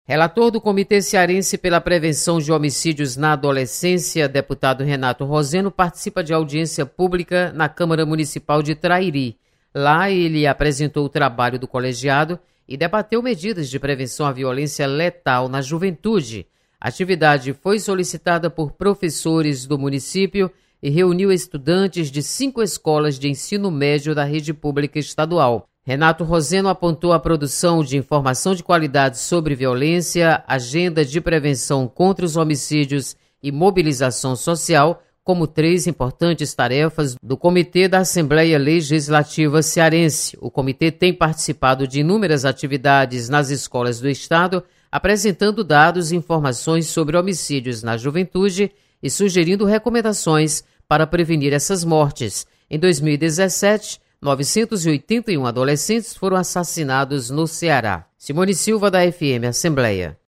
Deputado Renato Roseno apresenta trabalho do Comitê pela Prevenção de Homicídios na Adolescência.